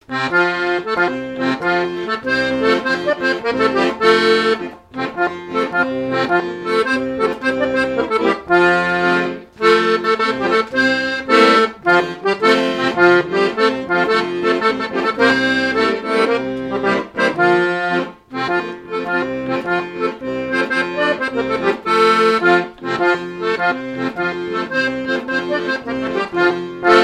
danse : quadrille : poule
airs de danses issus de groupes folkloriques locaux
Pièce musicale inédite